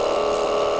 airutils_engine.ogg